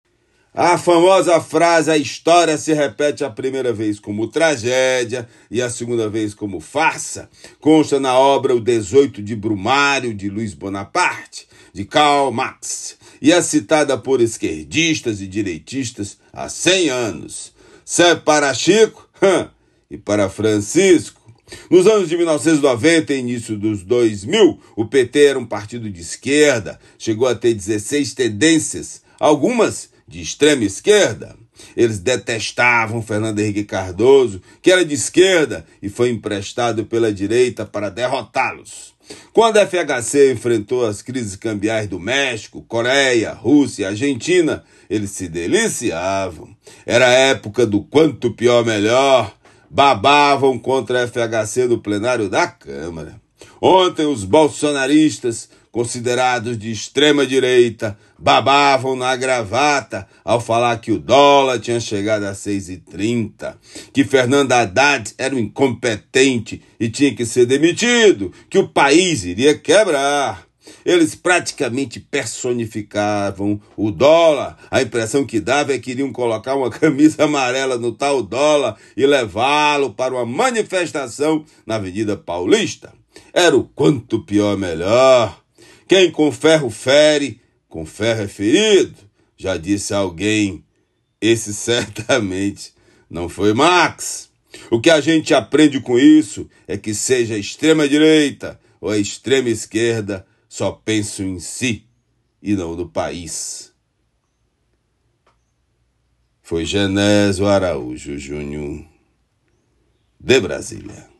Comentário
direto de Brasília.